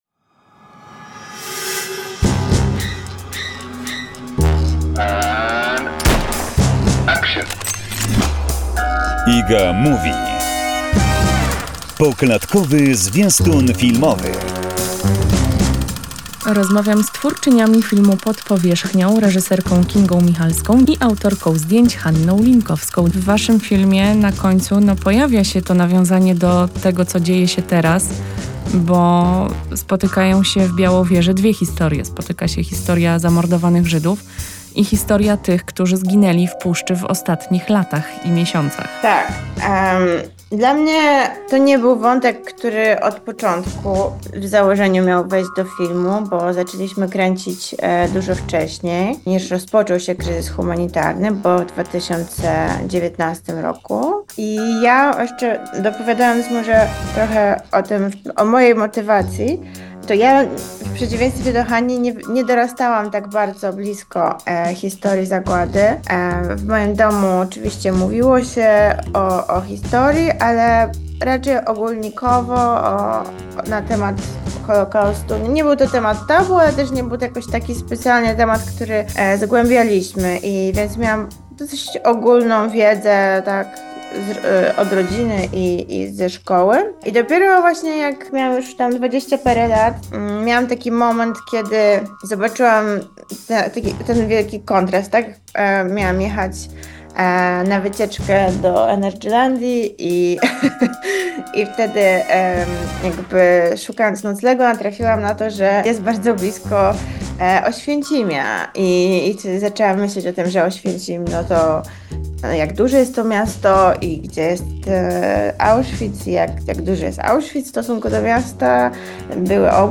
rozmowa o filmie „Pod powierzchnią” cz.2